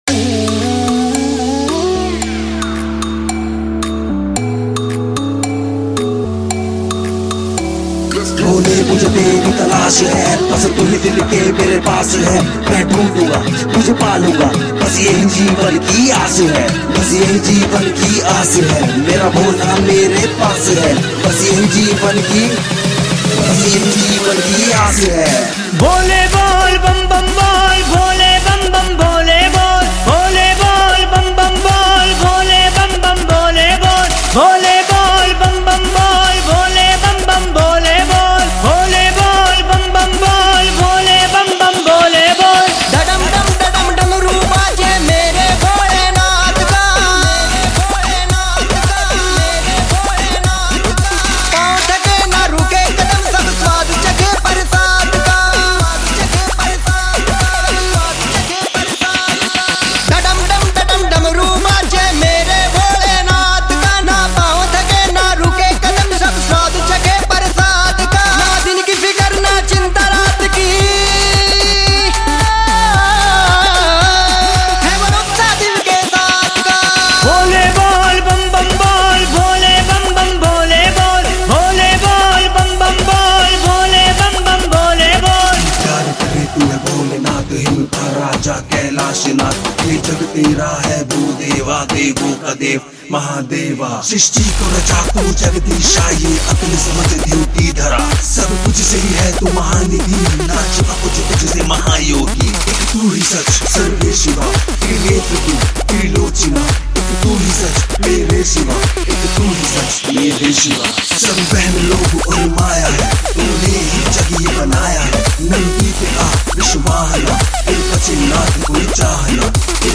[ Bhakti Songs ]